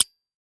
Звуки пистолета
Звук щелчка при нажатии на спусковой крючок